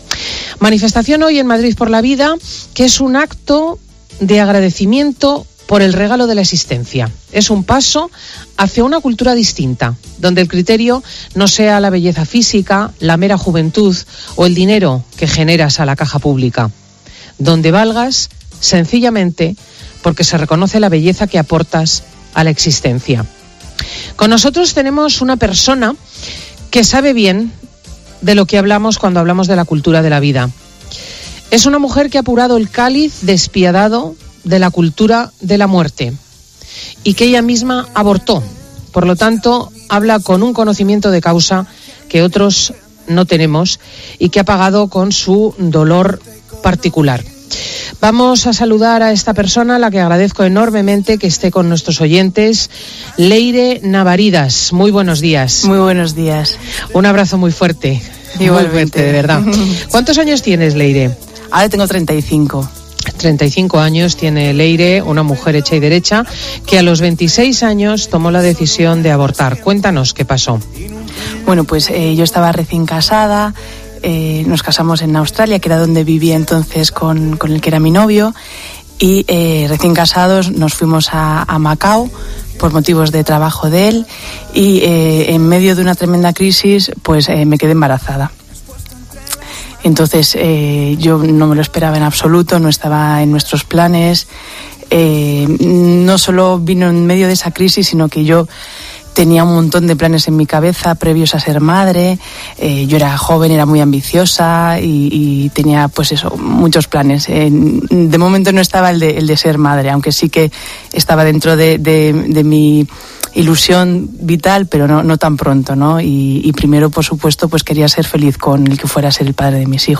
AUDIO: Escucha ahora 'El comentario de Cristina L. Schlichting', emitido el 14 de marzo, en FIN DE SEMANA . Presentado por Cristina López Schlichting,...